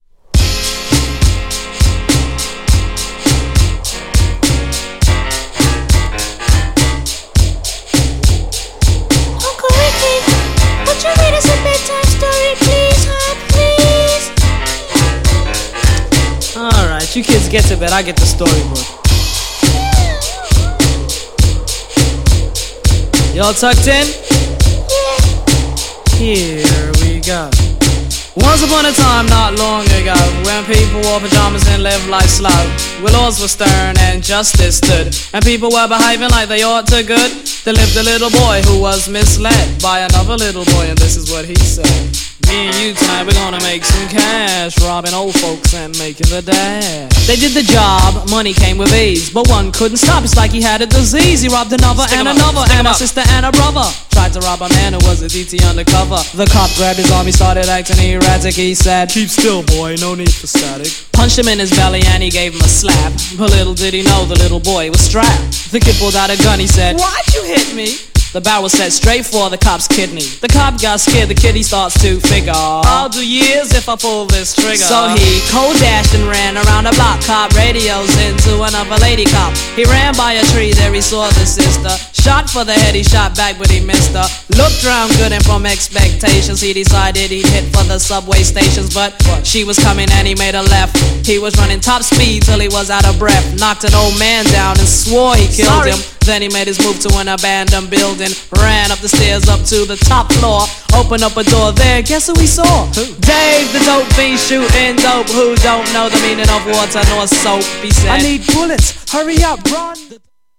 GENRE Hip Hop
BPM 131〜135BPM